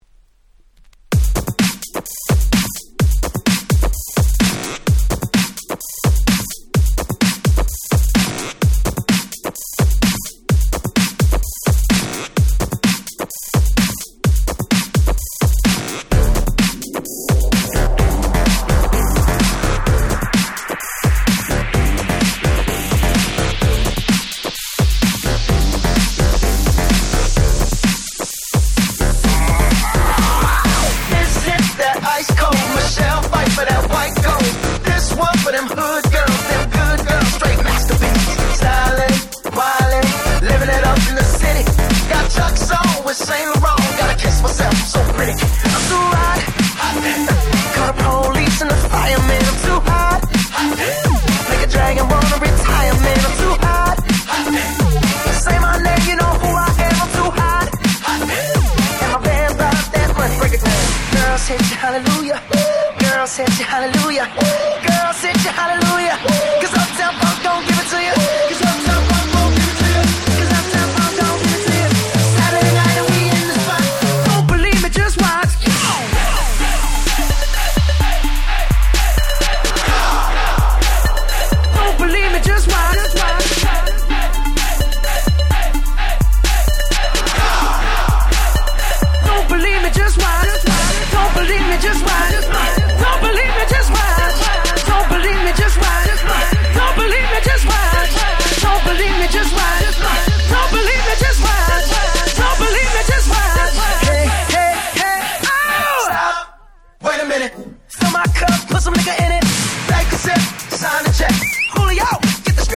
【Media】Vinyl 12'' Single (Double Pack)
自分が当時好んで使用していた曲を試聴ファイルとして録音しておきました。